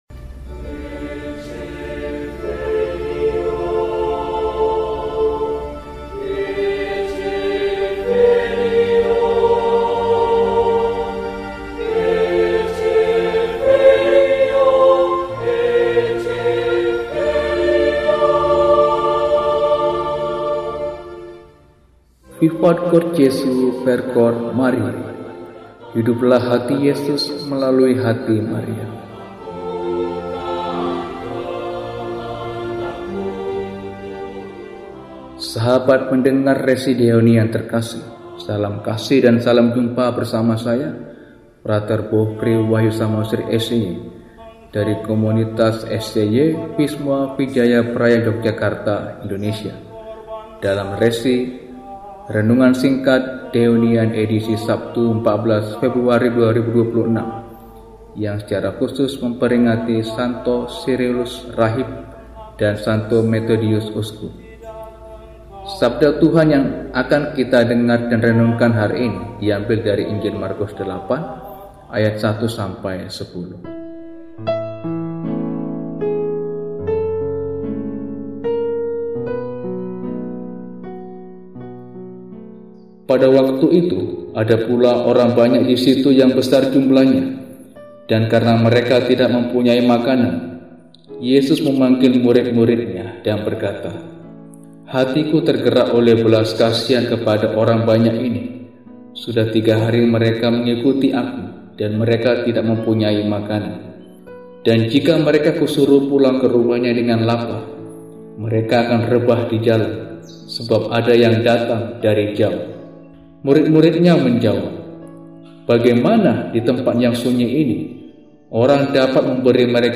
Sabtu, 14 Februari 2026 – Peringatan Wajib St. Sirilus, Pertapa dan Metodius, Uskup – RESI (Renungan Singkat) DEHONIAN